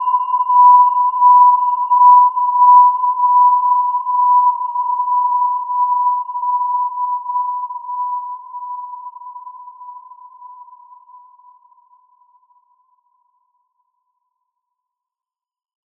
Gentle-Metallic-3-B5-mf.wav